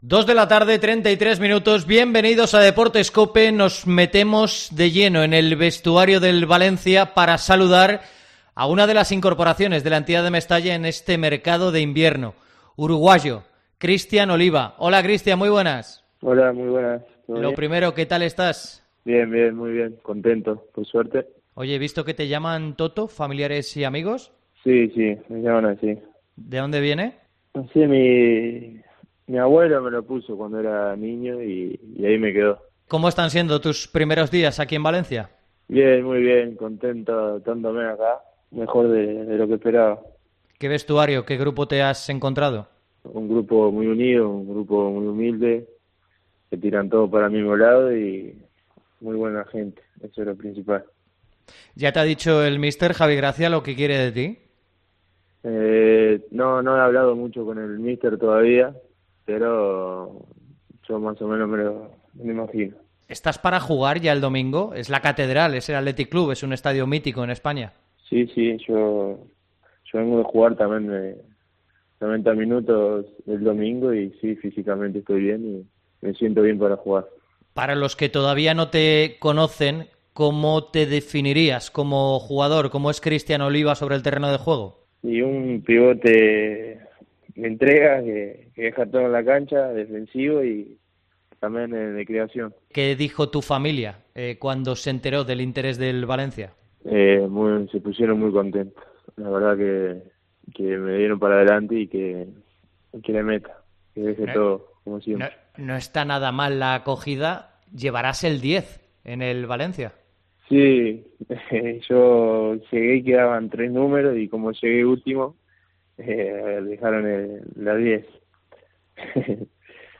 El centrocampista uruguayo concede su primera entrevista en España a Deportes COPE. Sueña con seguir en el Valencia CF
AUDIO. Entrevista a Christian Oliva en Deportes COPE Valencia